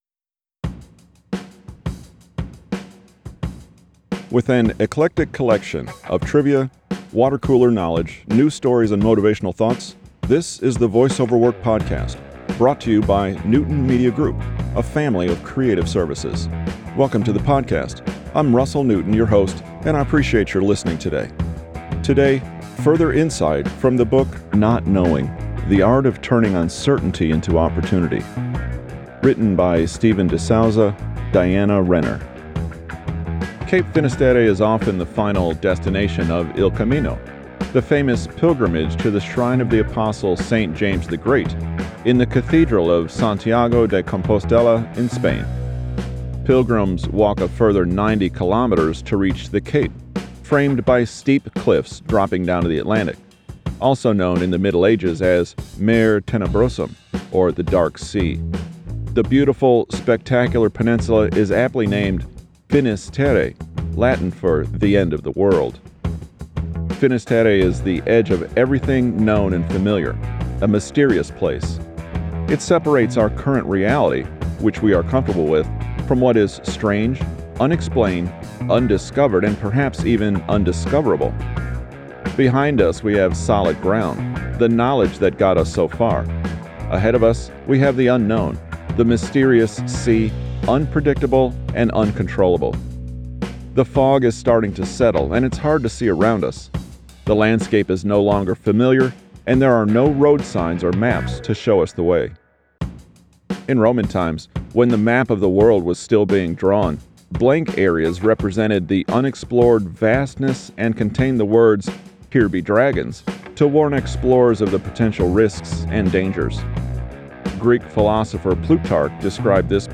Categories: Audiobook, Podcasts, Self ImprovementTags: , , , , , , ,